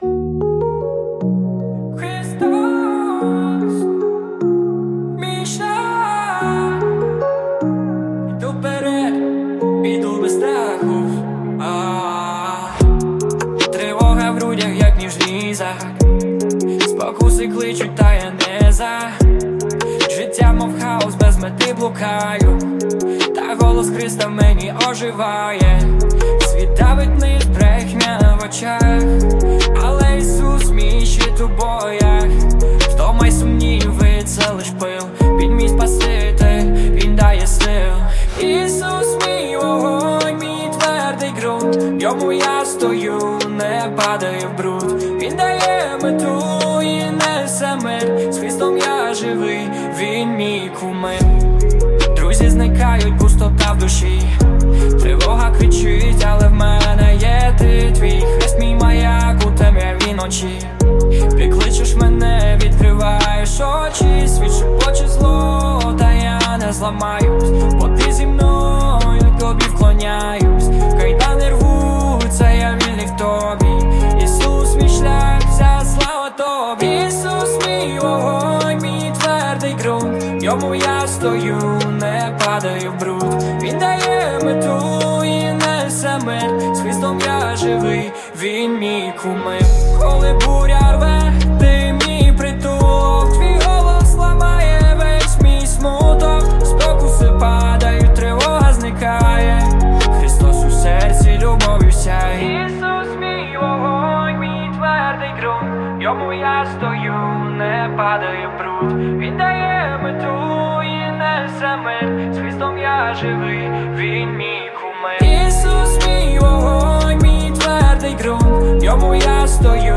песня
178 просмотров 342 прослушивания 14 скачиваний BPM: 150